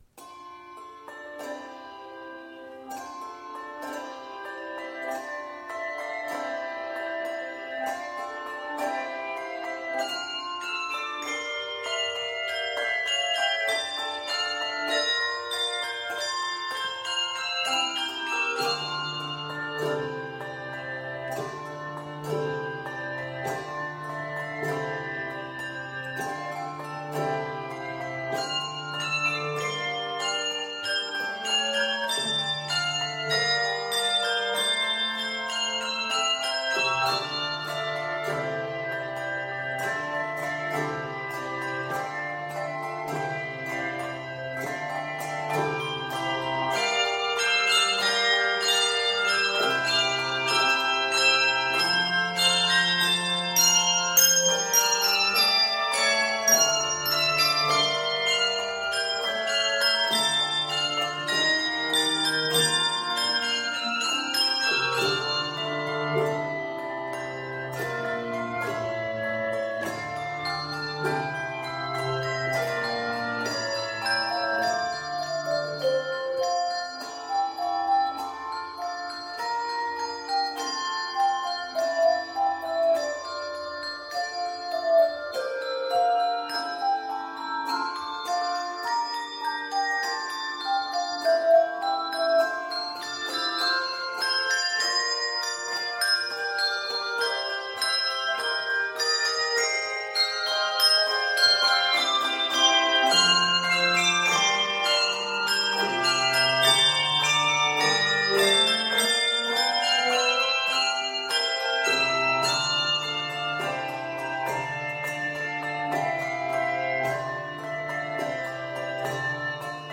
Octaves: 3-6